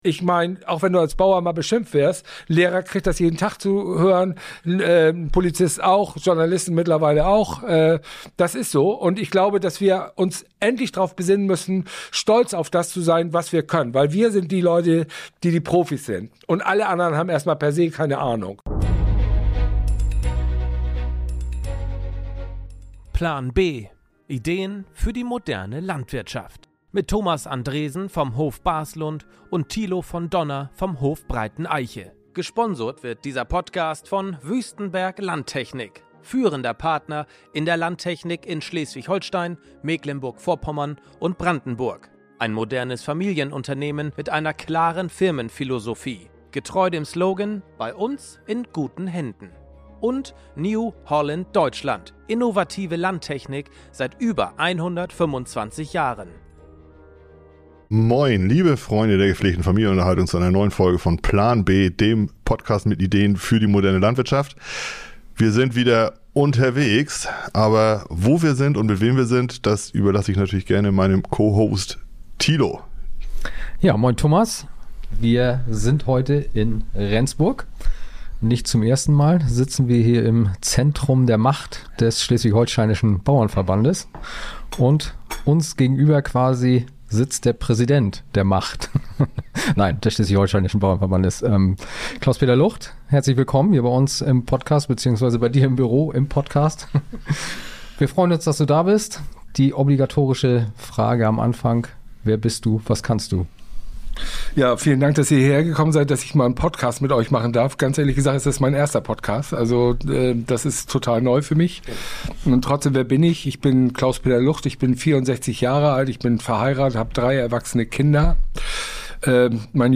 Klartext-Interview ~ Plan B - Ideen für die moderne Landwirtschaft Podcast